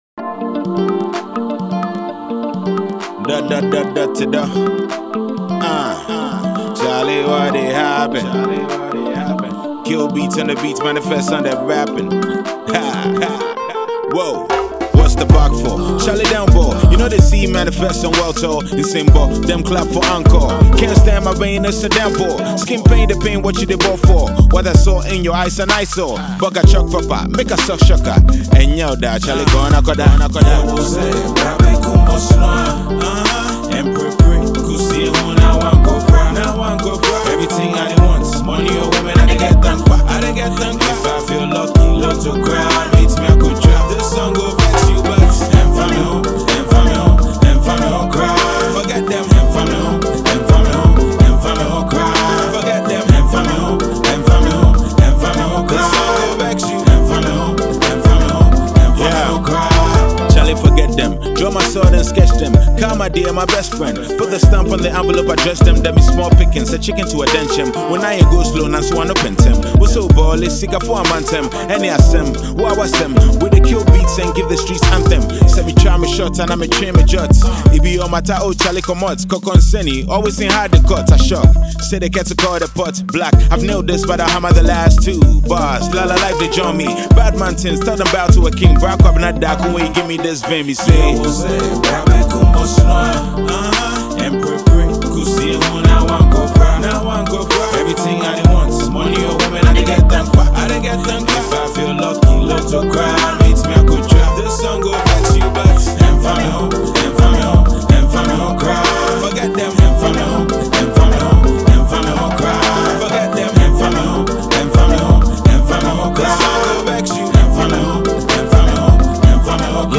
Ghanaian Rapper
Melodious Rap song